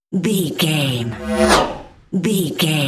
Pass by sci fi fast
Sound Effects
Fast
futuristic
pass by
car
vehicle